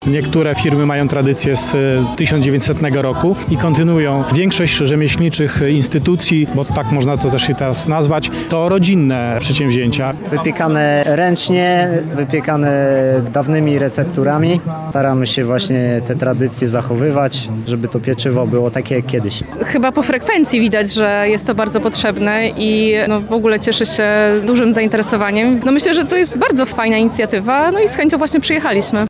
XXVI Lubelskie Święto Chleba odbywa się na terenie Muzeum Wsi Lubelskiej.